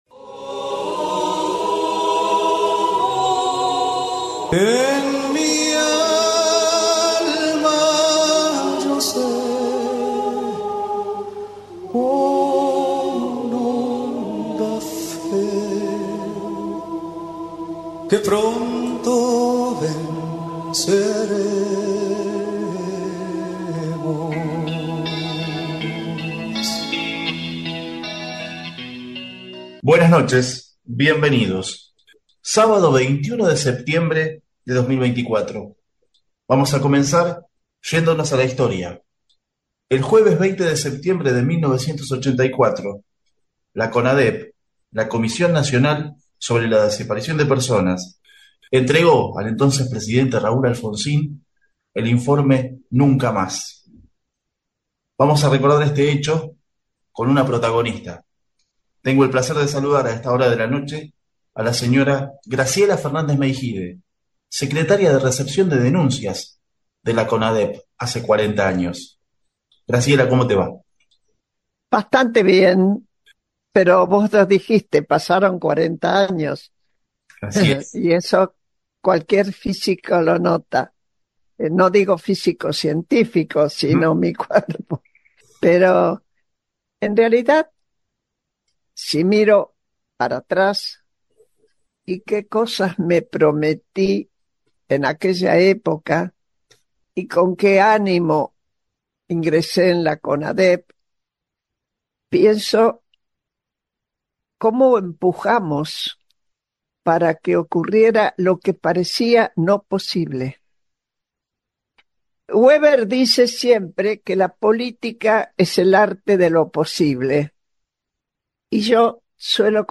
Tipo de documento: Testimonio